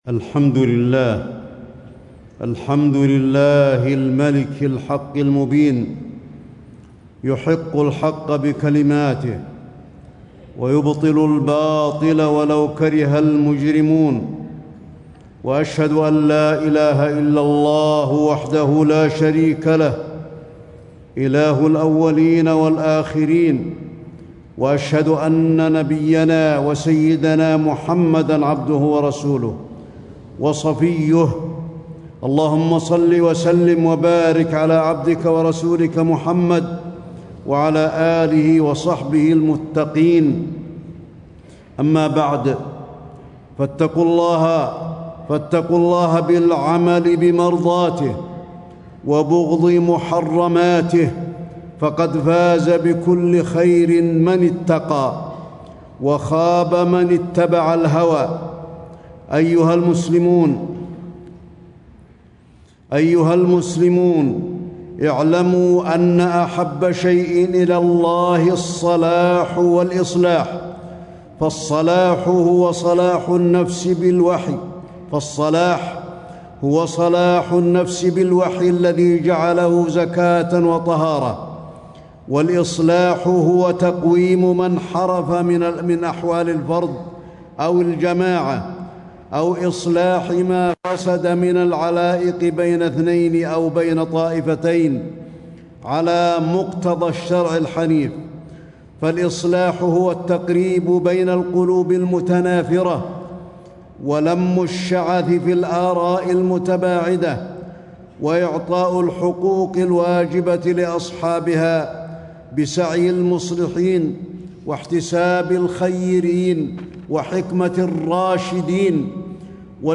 تاريخ النشر ٢٧ صفر ١٤٣٦ هـ المكان: المسجد النبوي الشيخ: فضيلة الشيخ د. علي بن عبدالرحمن الحذيفي فضيلة الشيخ د. علي بن عبدالرحمن الحذيفي فضل إصلاح ذات البين